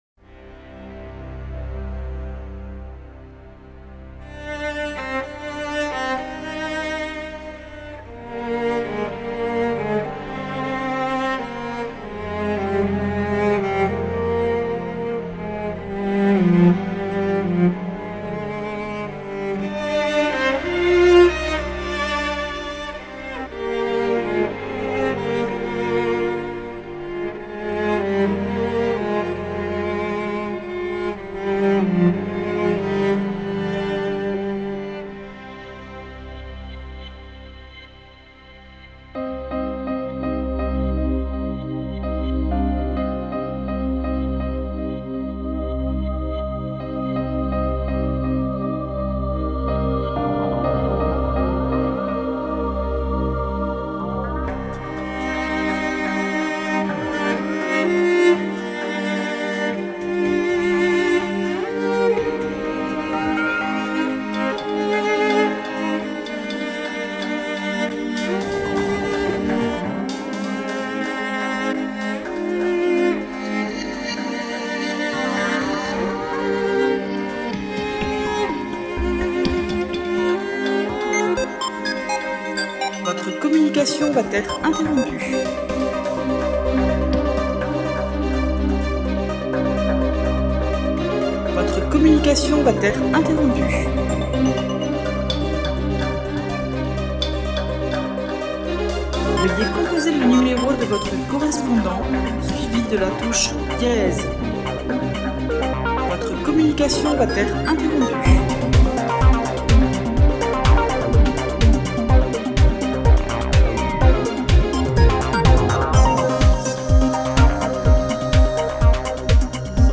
اثری سوزناک و آرامبخش
نوع آهنگ: لایت]